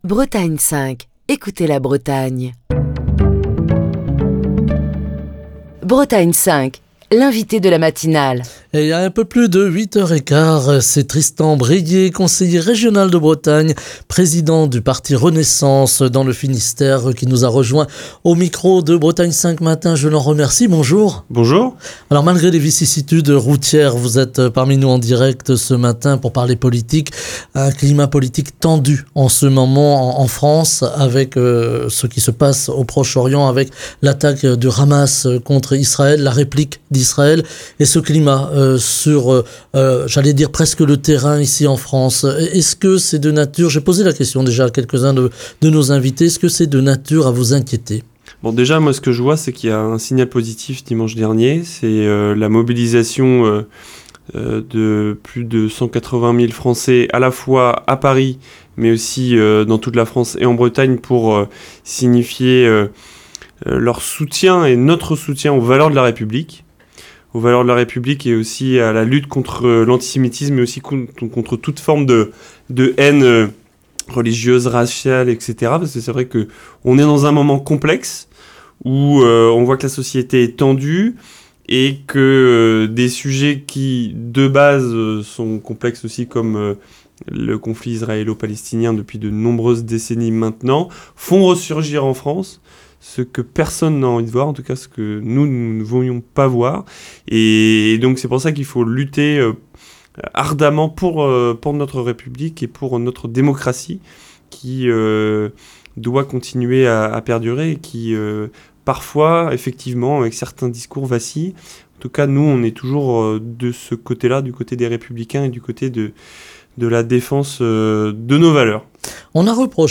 Alors que Renaissance tient son Campus régional demain à Morlaix, Tristan Bréhier, Conseiller régional de Bretagne, président du parti Renaissance Finistère est l'invité politique de Bretagne 5 Matin.